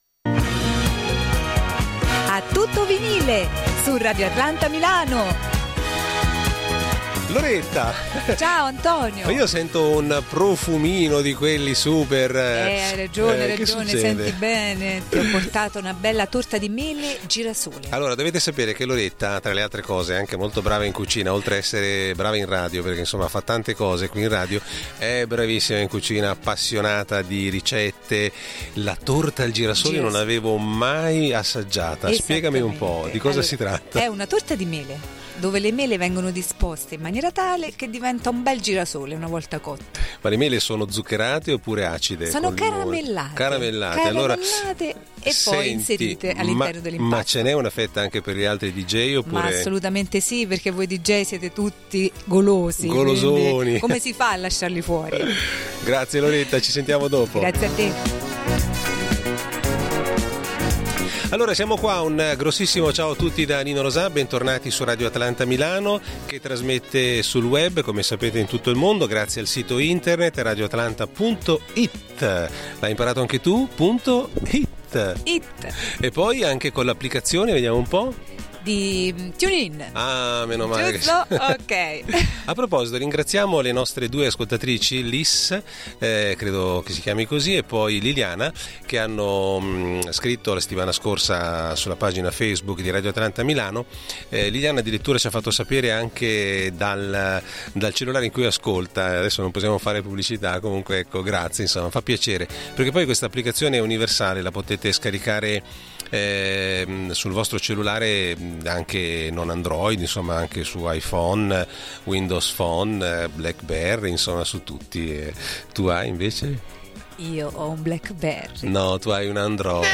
Musica “a tutto Vinile” ma anche notizie sugli artisti senza tempo della black dance, considerati i primi della classe.